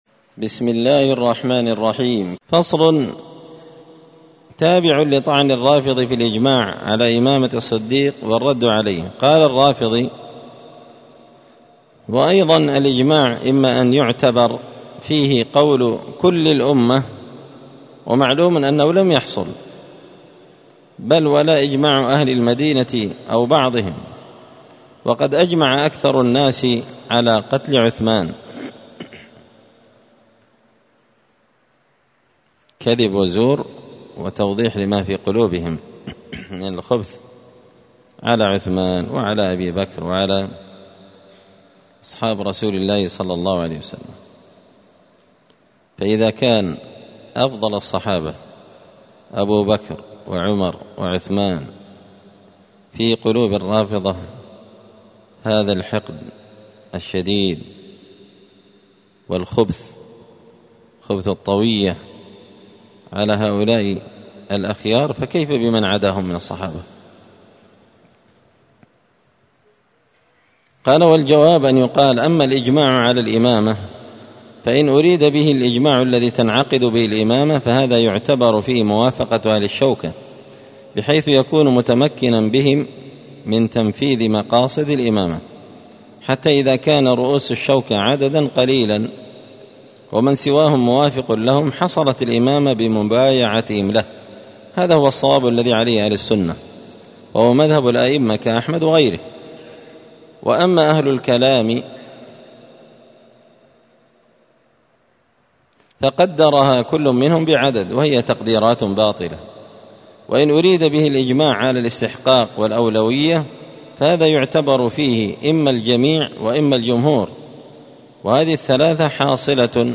*الدرس الثامن والثلاثون بعد المائتين (239) فصل في طعن الرافضي في الاحتجاج بالإجماع وبيان أن ذلك يهدم بنيانه*